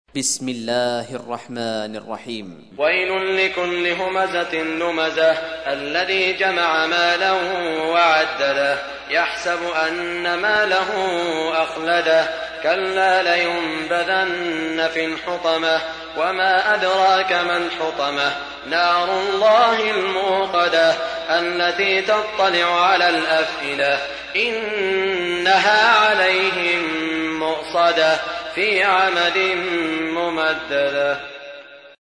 سورة الهمزة | القارئ سعود الشريم